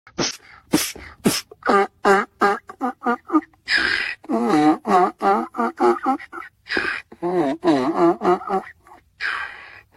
Donkey Beatboxer MP3 Download
The Donkey Beatboxer sound button is from our meme soundboard library
Donkey-Beatboxer.mp3